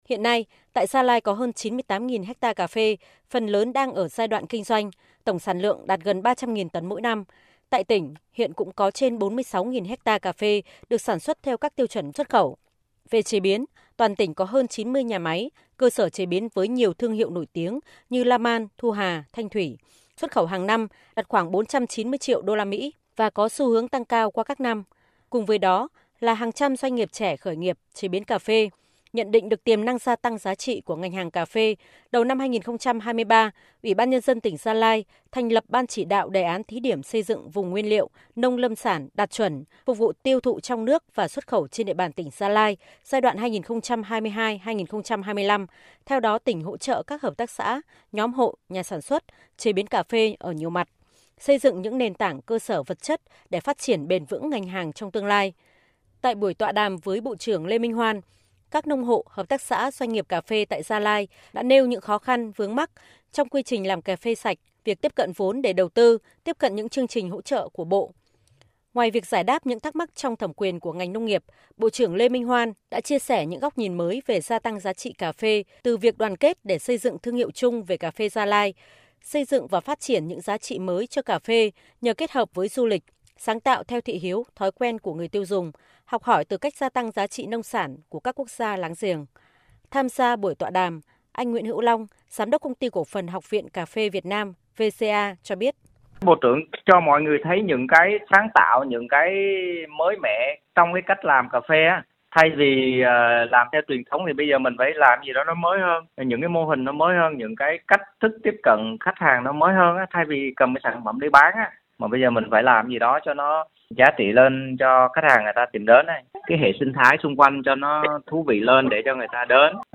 VOV1 - Chiều 11/11, nhân chuyến thăm và dự Tuần lễ Văn hoá Du lịch Gia Lai 2023, Bộ trưởng Bộ Nông nghiệp và Phát triển Nông thôn Lê Minh Hoan đã có buổi gặp gỡ, tọa đàm cùng các doanh nghiệp làm cà phê ở địa phương xoay quanh chủ đề nâng cao giá trị ngành hàng cà phê.